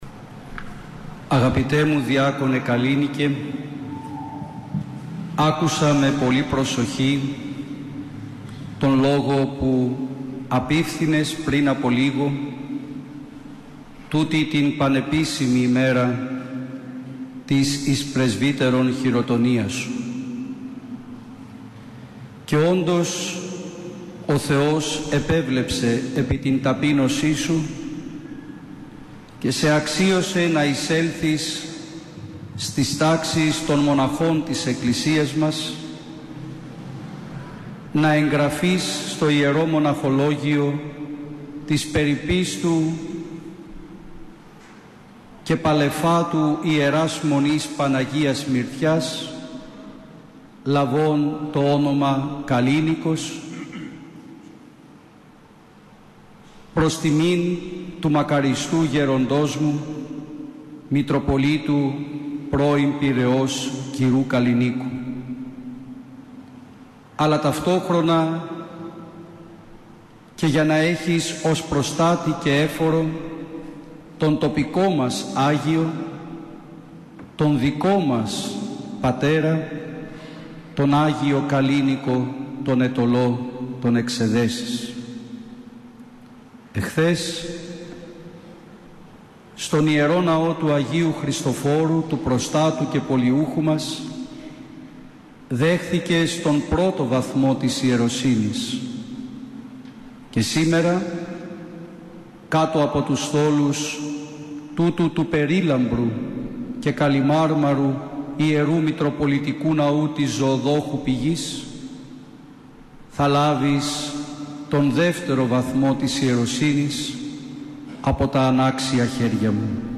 Ακούστε εδώ όλη την ομιλία του Σεβασμιωτάτου